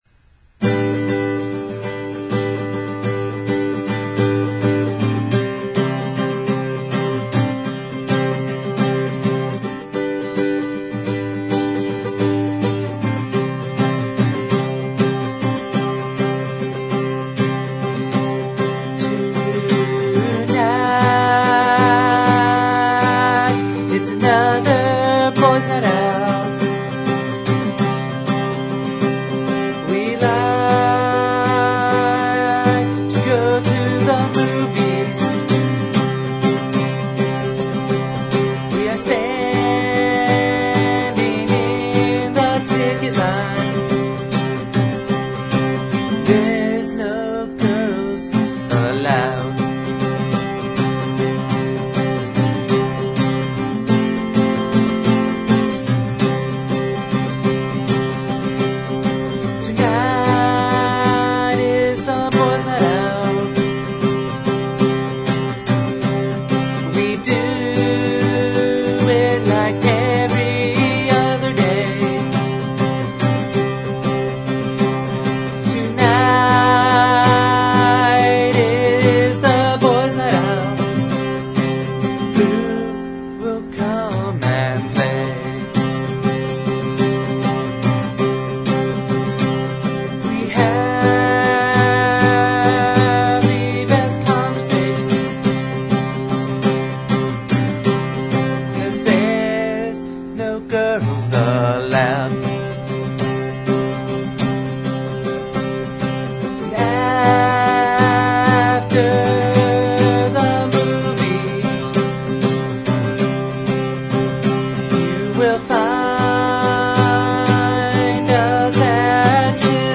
The songs on the site are rough cuts.